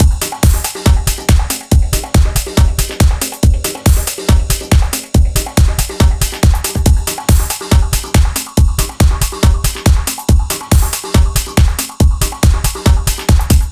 Сэмплы ударных (Техно-транс): Compression A
Тут вы можете прослушать онлайн и скачать бесплатно аудио запись из категории «Techno Trance».